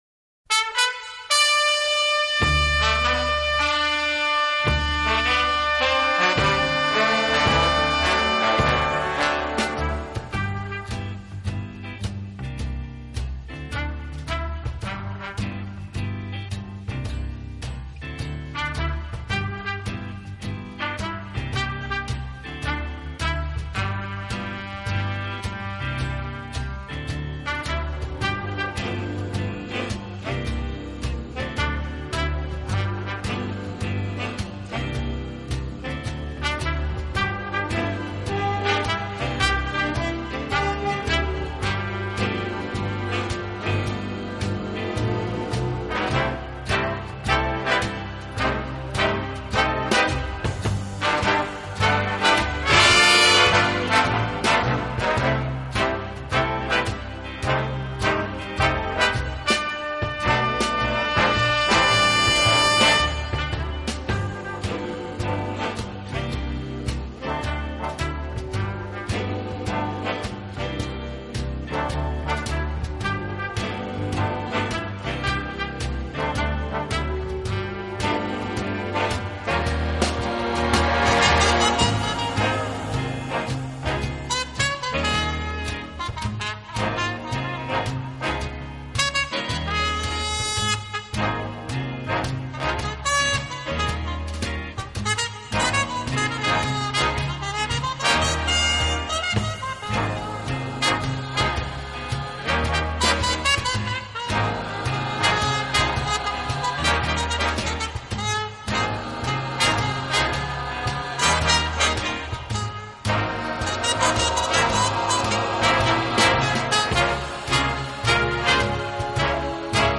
乐队的基本特征是突出
温情、柔软、浪漫是他的特色，也是他与德国众艺术家不同的地方。